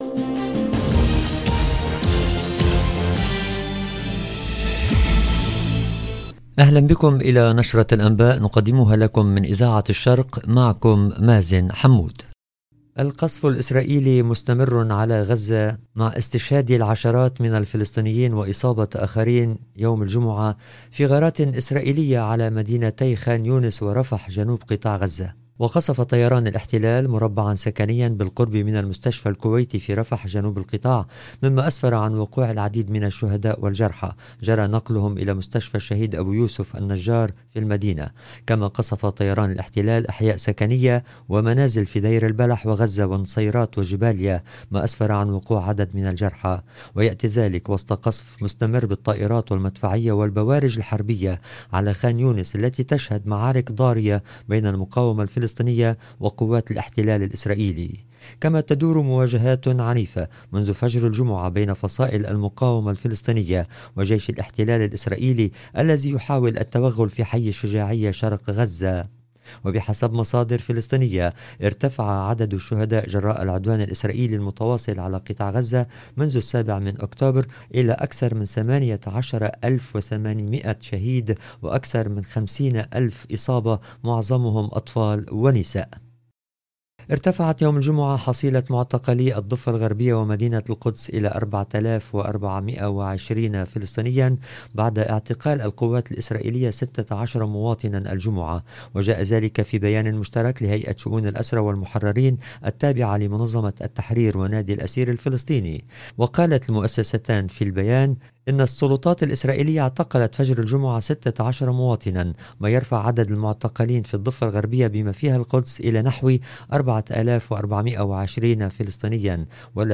LE JOURNAL EN LANGUE ARABE DU SOIR DU 15/12/23